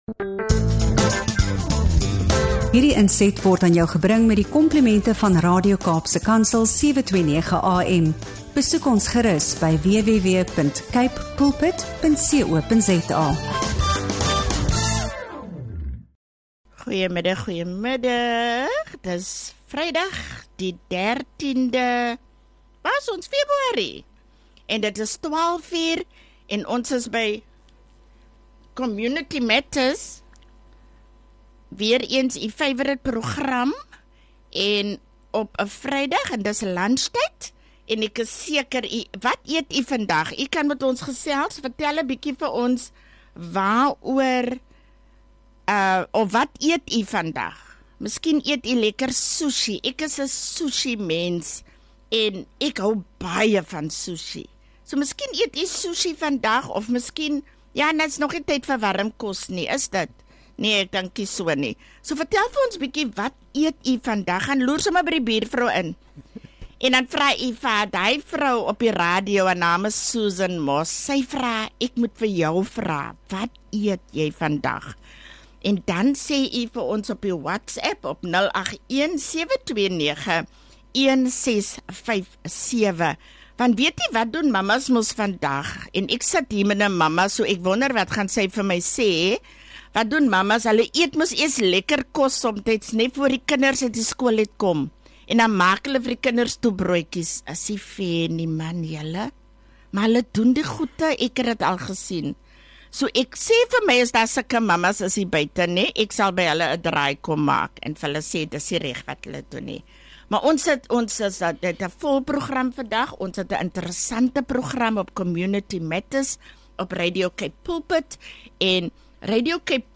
Met ’n passie om vroue te bemoedig, herinner sy ons daaraan dat ons nie alleen is nie en dat herstel moontlik is. Moenie hierdie inspirerende gesprek oor geloof, diensbaarheid, veerkragtigheid en hoop misloop nie.